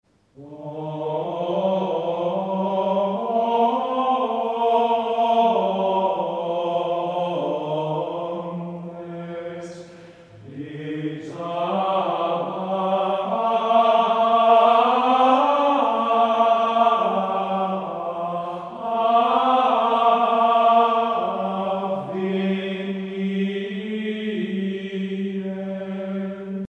Musique médiévale
Pièce musicale éditée